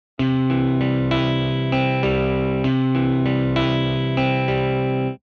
▶アルペジオ奏法を行った例
Arpeggio-01-Left.mp3